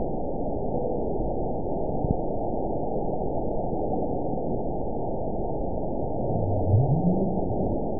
event 921702 date 12/16/24 time 22:46:30 GMT (4 months, 2 weeks ago) score 8.95 location TSS-AB04 detected by nrw target species NRW annotations +NRW Spectrogram: Frequency (kHz) vs. Time (s) audio not available .wav